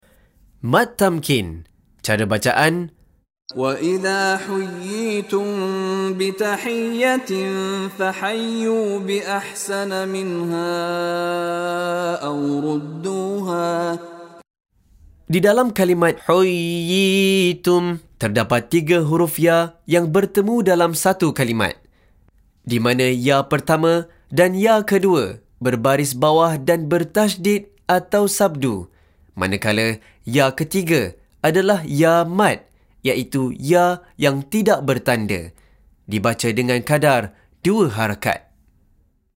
Penerangan Hukum + Contoh Bacaan dari Sheikh Mishary Rashid Al-Afasy
DIPANJANGKAN sebutan huruf Mad (2 harakat)